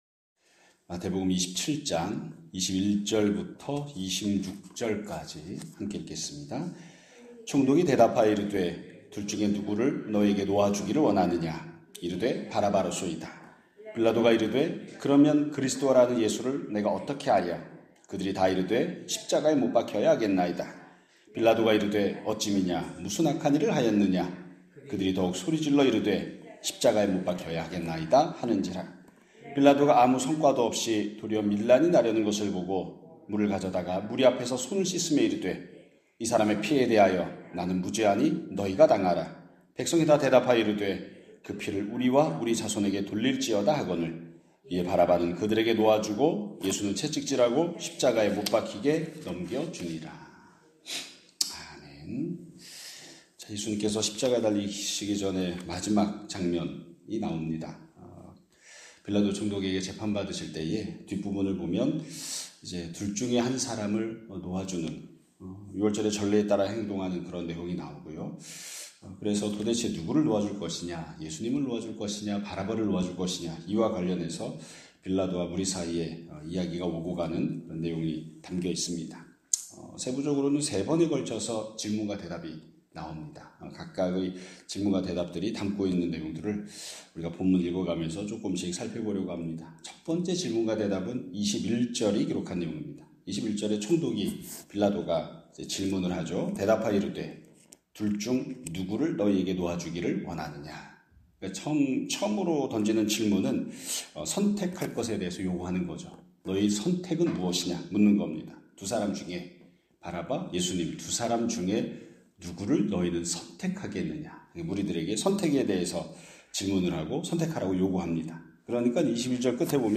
2026년 4월 17일 (금요일) <아침예배> 설교입니다.